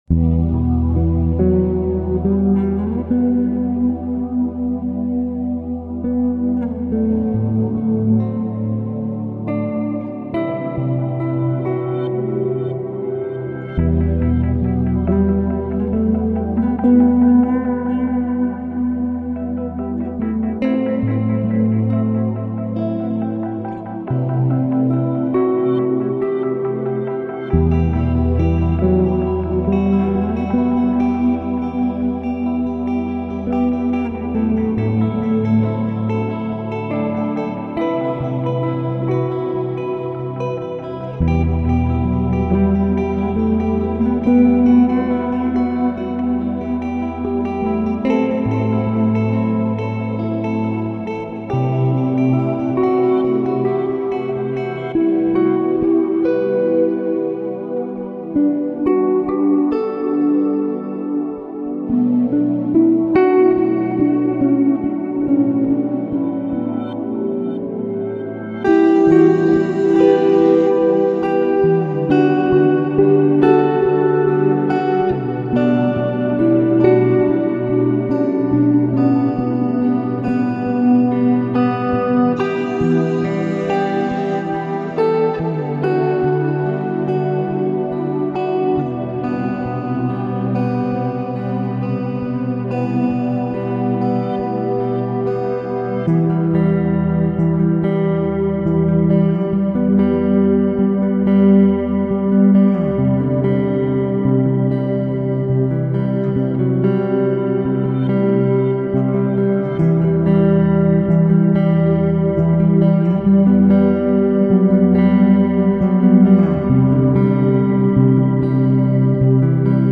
Lo-Fi, Lounge, Ambient, Chillout 音频编解
11首曲目，温暖而郁郁葱葱的低保真感觉。